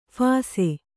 ♪ phāse